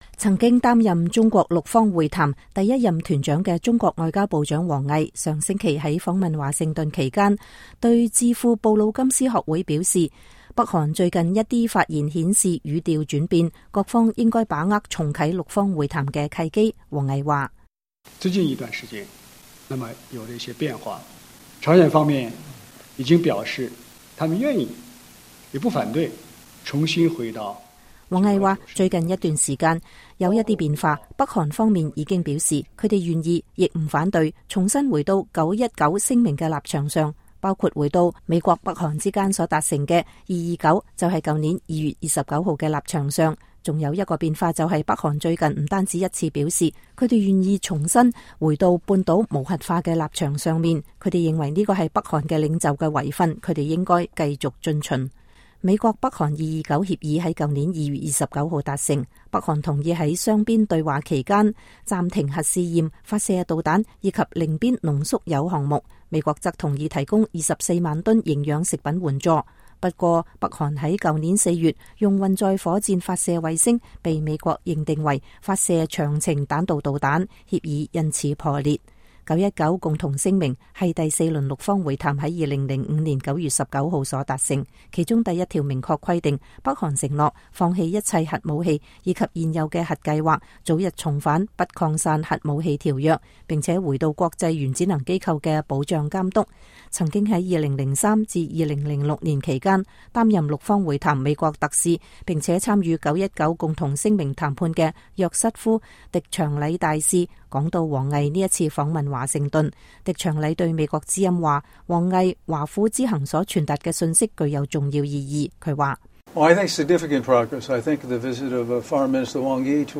專訪前美國六方會談特使約瑟夫.狄長禮